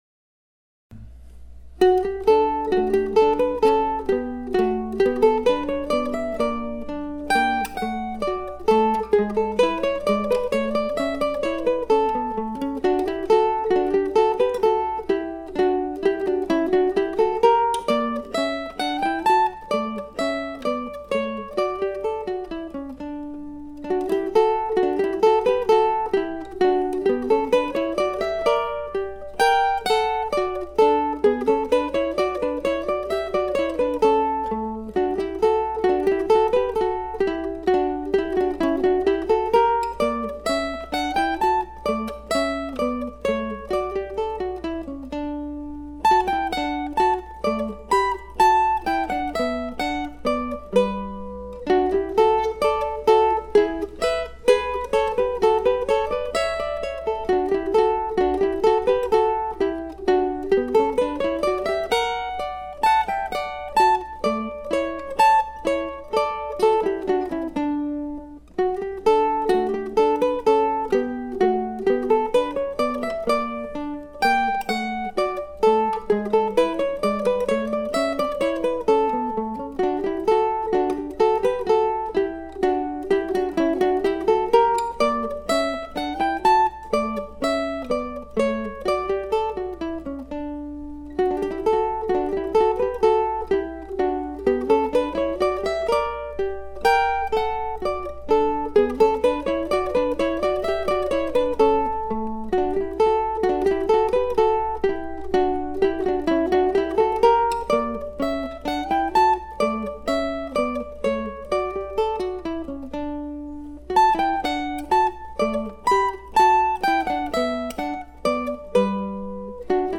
This is the mandolin duo version of a waltz that I wrote back then and recorded with guitar and mandolin in March 2008.
AlicesGardenduet.mp3